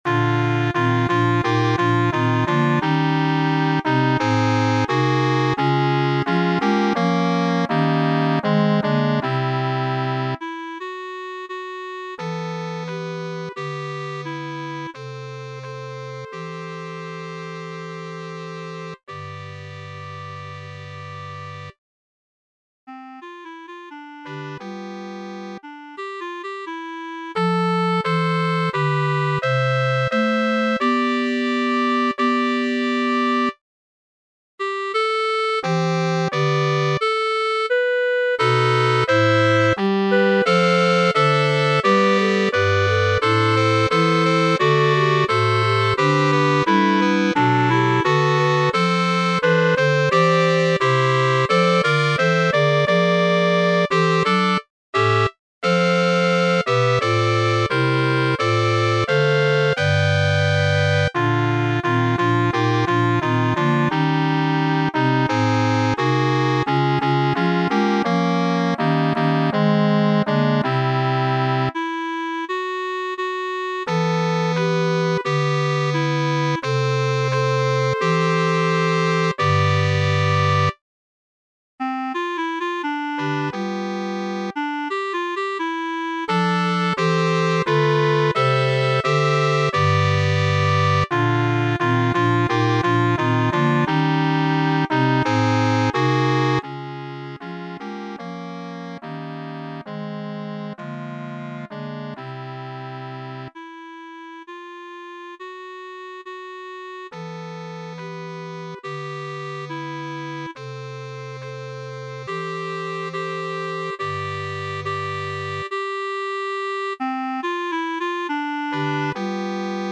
SSATB (5 voix mixtes) ; Partition complète.
Tonalité : do (centré autour de)